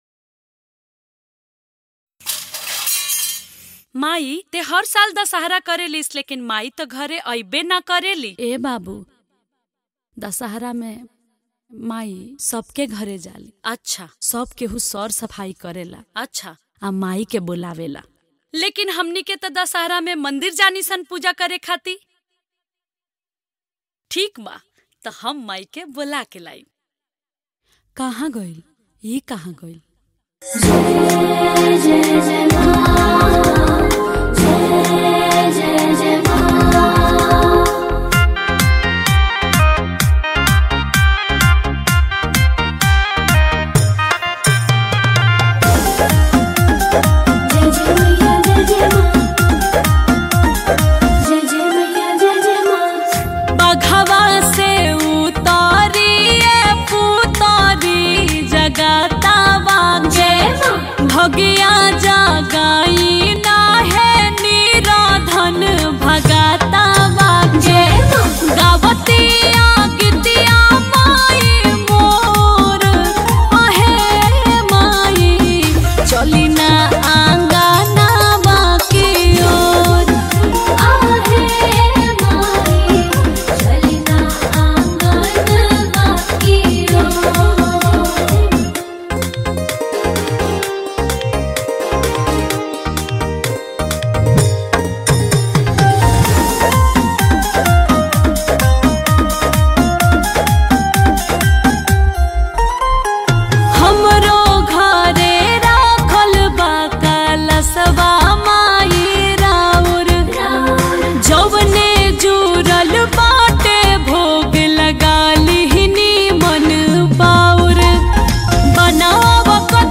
Bhagti